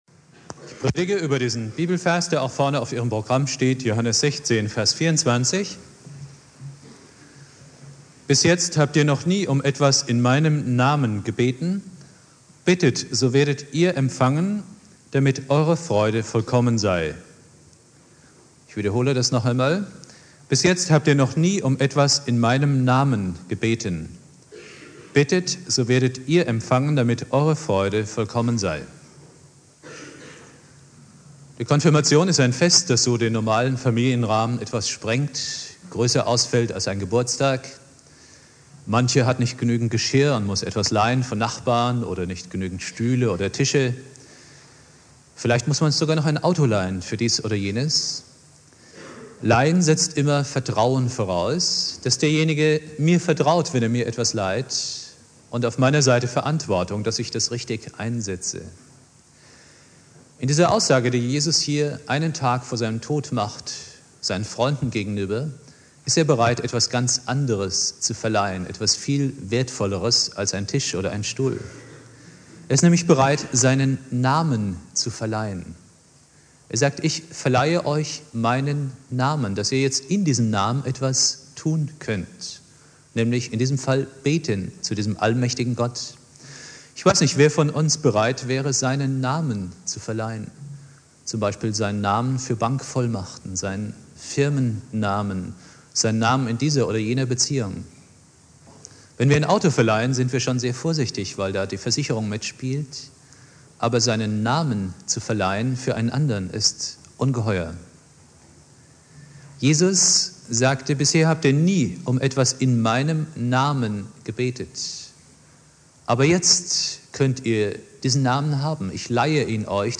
Predigt
Bittet, so werdet ihr empfangen, damit Eure Freude vollkommen sein" - Konfirmation Obertshausen Bibeltext: Johannes 16,24 Dauer: 23:59 Abspielen: Ihr Browser unterstützt das Audio-Element nicht.